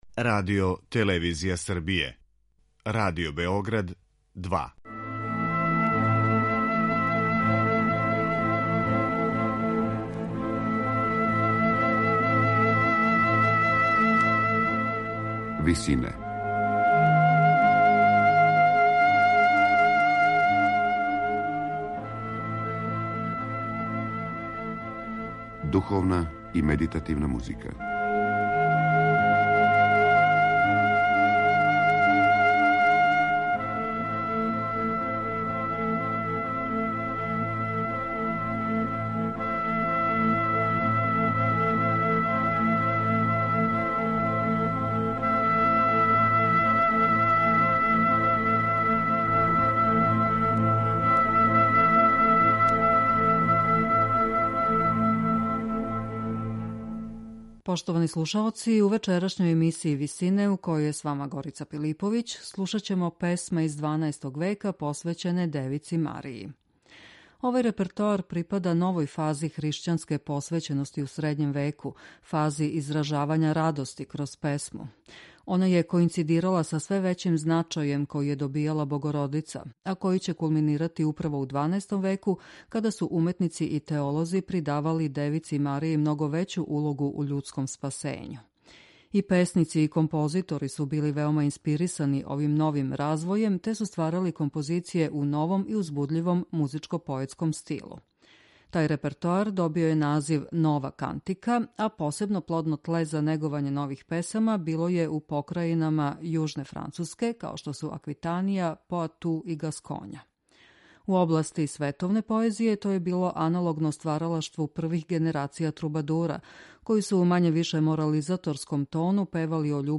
песме из XII века посвећене Богородици у извођењу ансамбла Перегрина